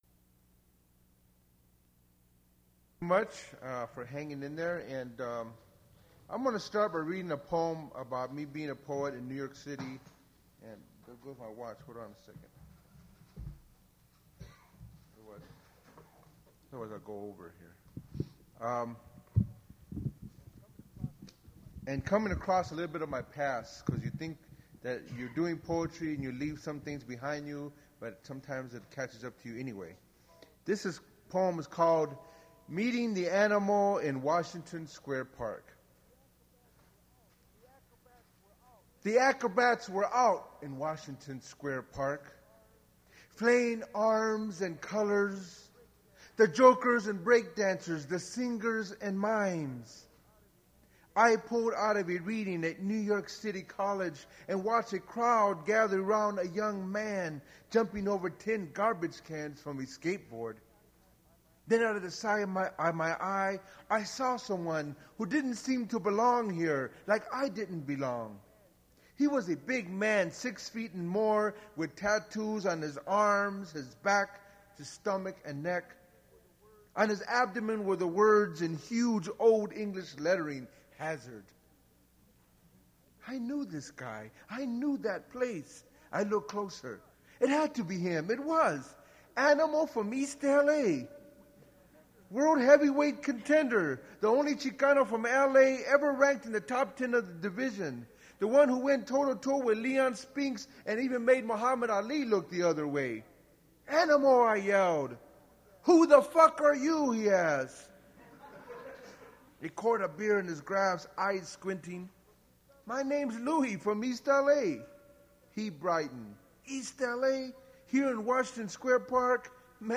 Attributes Attribute Name Values Description Luis Rodriguez poetry reading at Duff's Restaurant.
mp3 edited access file was created from unedited access file which was sourced from preservation WAV file that was generated from original audio cassette. Language English Identifier CASS.750 Series River Styx at Duff's River Styx Archive (MSS127), 1973-2001 Note recording starts in the middle of the performance; cut informational material about events/readings that was at the end.